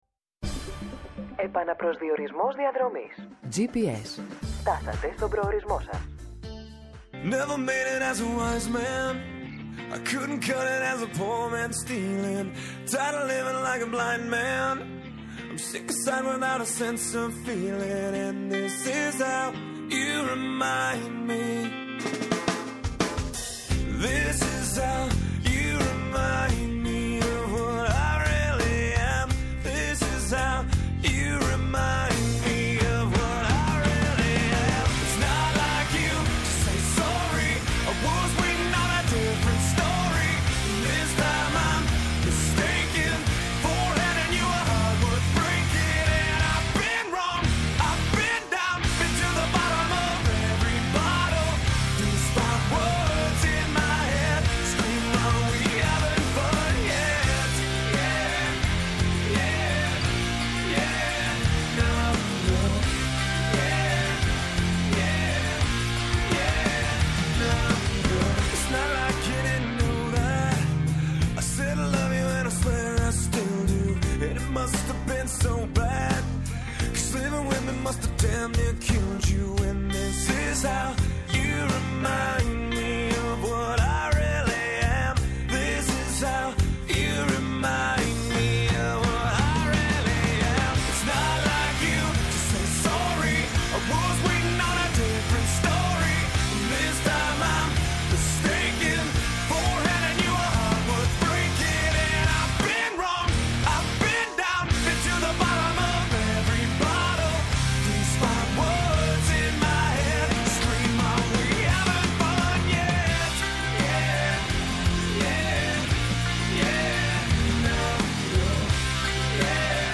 -Ο Χρήστος Σταϊκούρας, υπουργός Υποδομών και Μεταφορών
-O Αλκιβιάδης Στεφανής, στρατηγός εν αποστρατεία και πρώην υφυπουργός Εθνικής Άμυνας